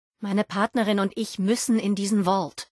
Kategorie:Fallout 76: Audiodialoge Du kannst diese Datei nicht überschreiben.